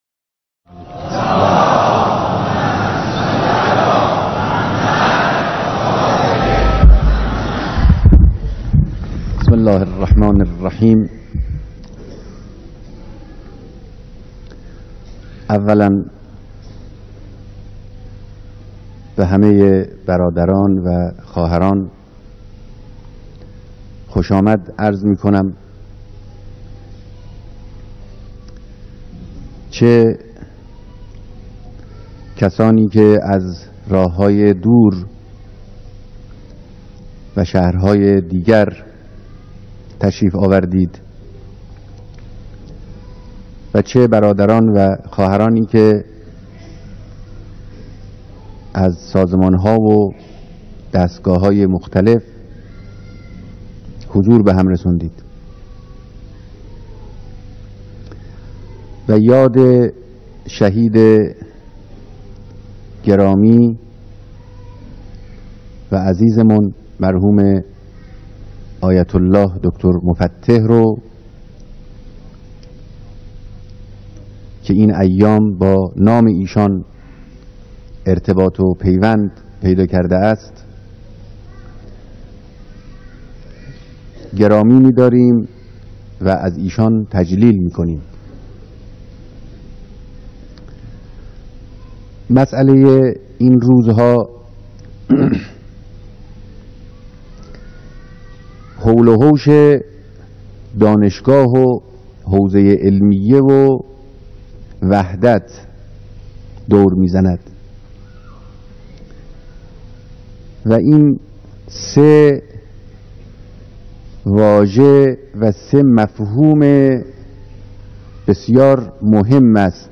بیانات رهبر انقلاب در دیدار جمعی از دانشجویان و طلاب